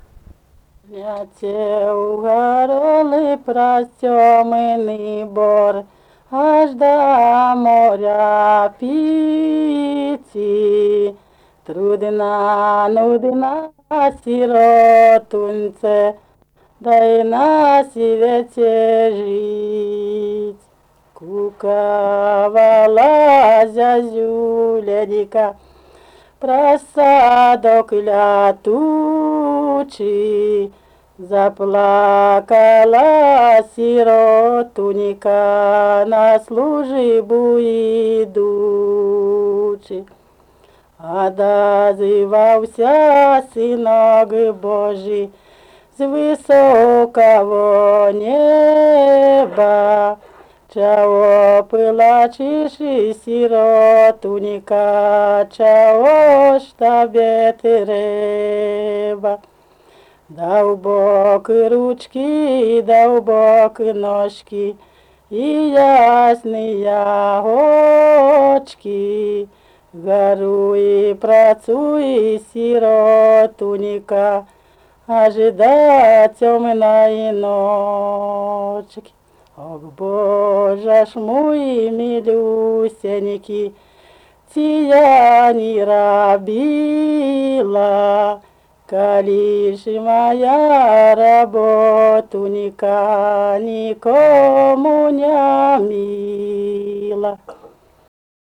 daina
Petrikai (Pyetryki), Baltarusija
vokalinis